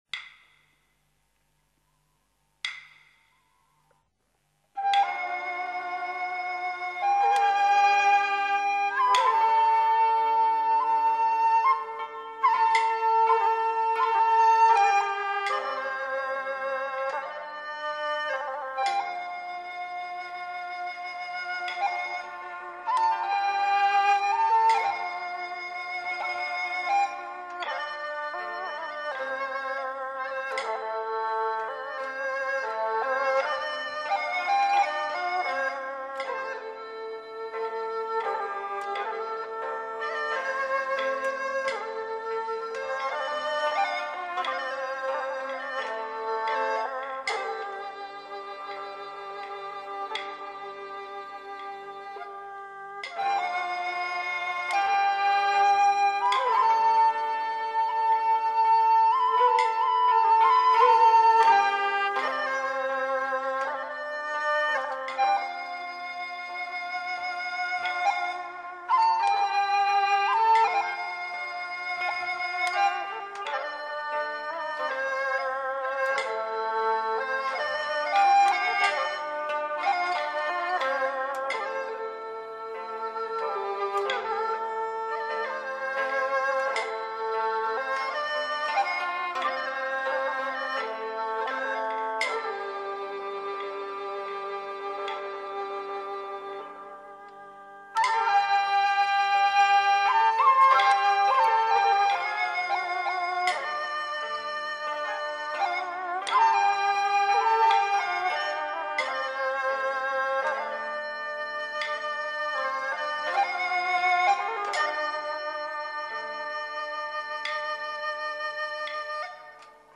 昆曲曲牌